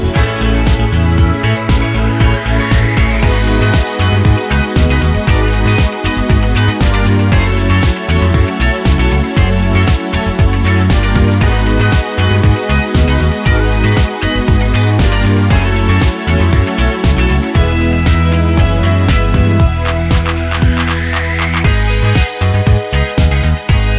Twofers Medley Pop (2000s) 4:14 Buy £1.50